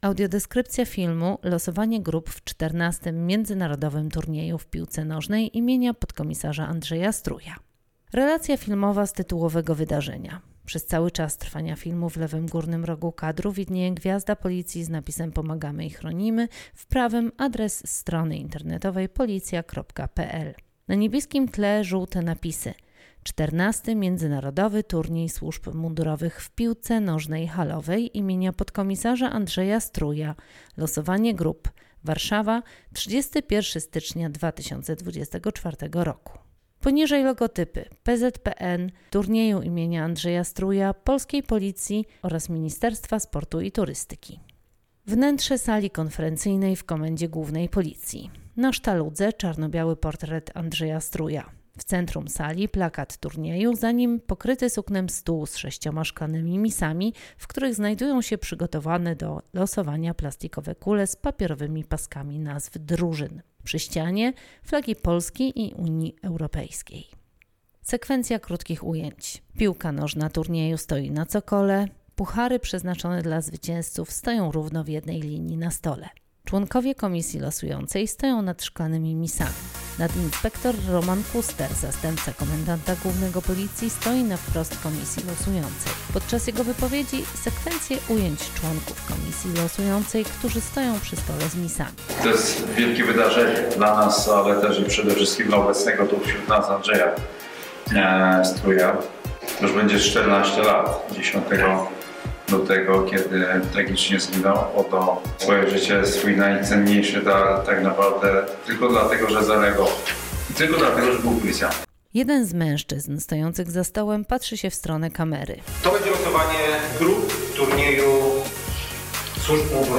Nagranie audio Audiodeskrypcja do filmu: Losowanie grup do piłkarskiego turnieju im. podkom. Andrzeja Struja